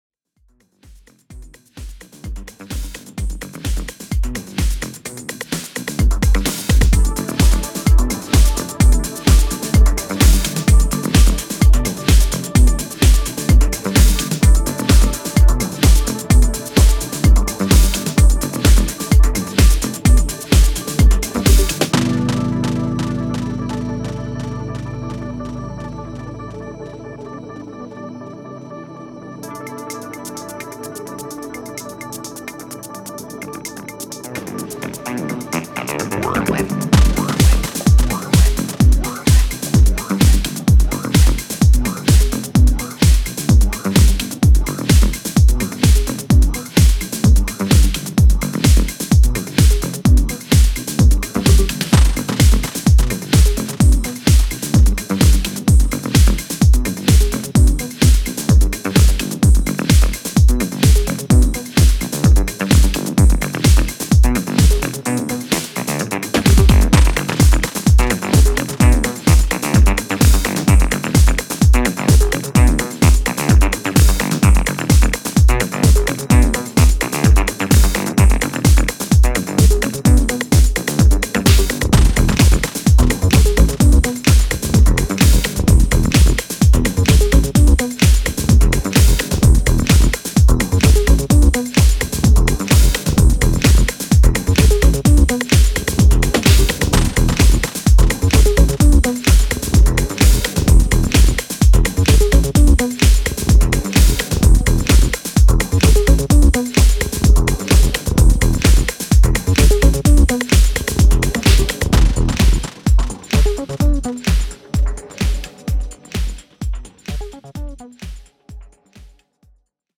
いずれの楽曲からもそれぞれの個性が溢れており、広範なタイプのミニマル・ハウスを収録した一枚です！